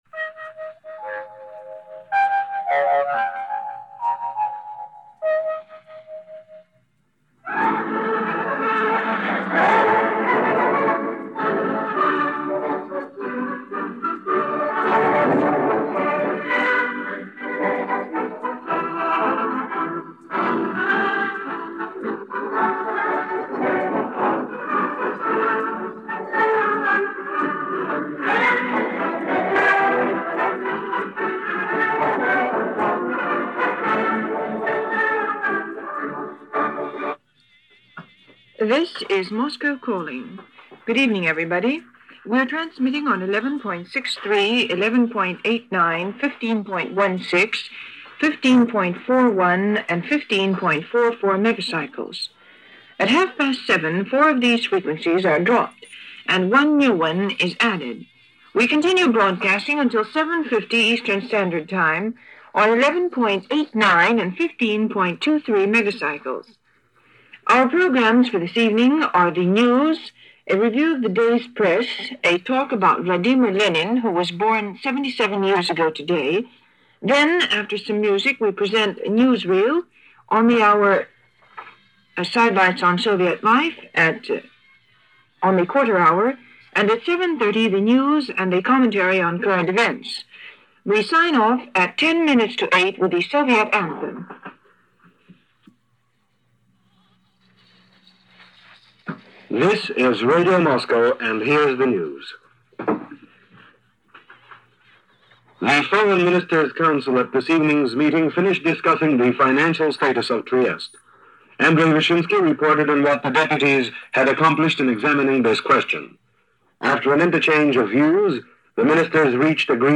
Radio Moscow for this April 22, 1947 – News from the English Service of the shortwave station.